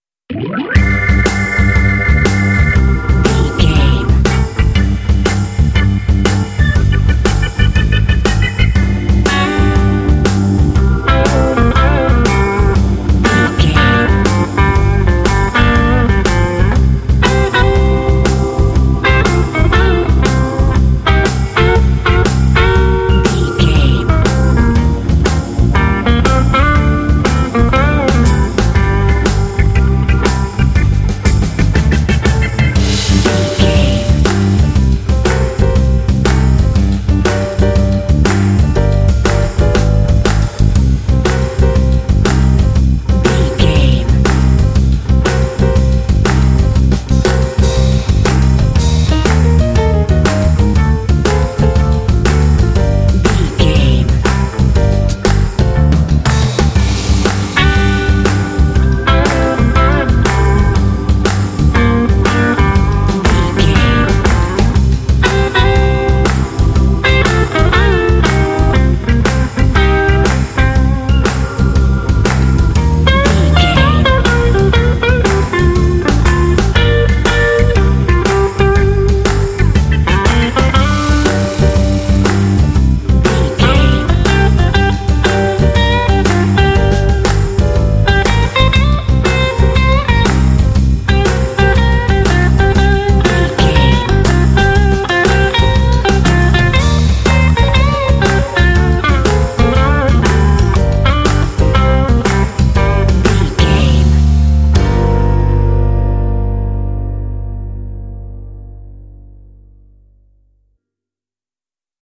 Aeolian/Minor
groovy
smooth
electric guitar
electric organ
piano
drums
bass guitar
blues
jazz